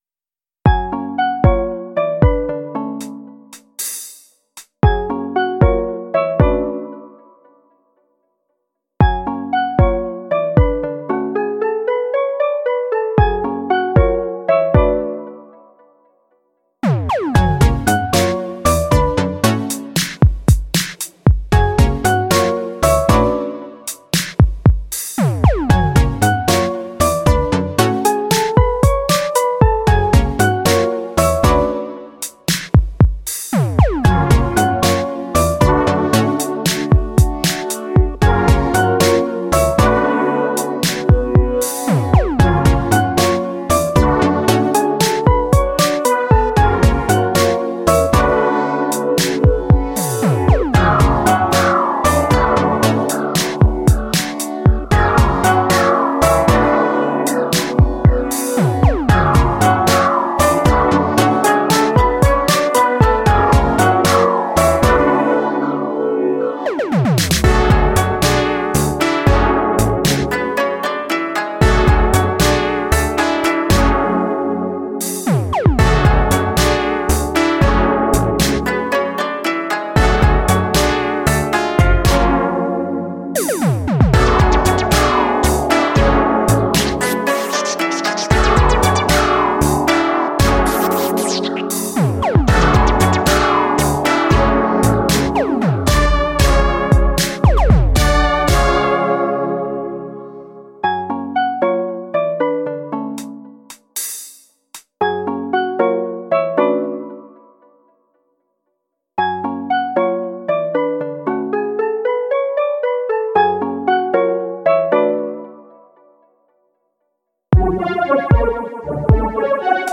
B面のカップリング曲もA面同様、キャッチーなフックが印象的でインパクト大！